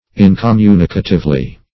-- In`com*mu"ni*ca*tive*ly, adv. --